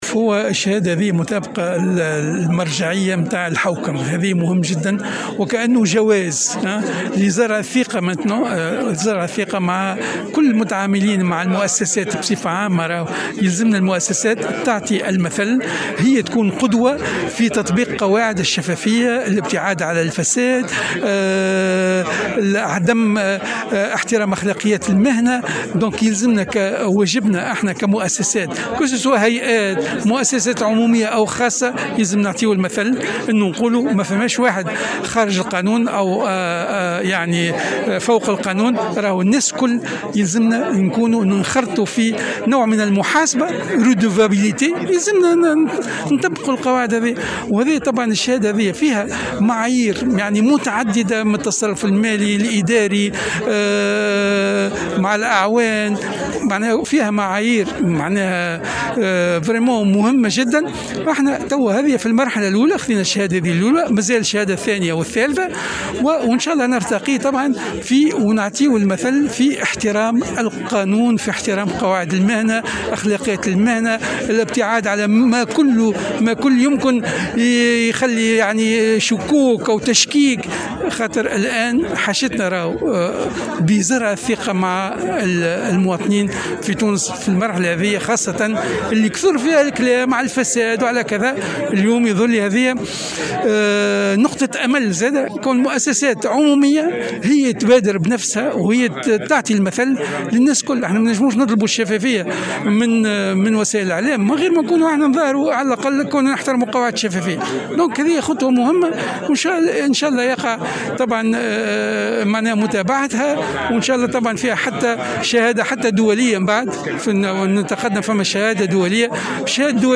وقال رئيس الهيئة، النوري اللجمي في تصريح للجوهرة أف أم، بالمناسبة، إن رئيسة الحكومة نجلاء بودن لم تستجب لطلبات الهيئة لتنظيم لقاء معها من أجل الحديث حول الملفات العالقة في القطاع وفي الهيئة .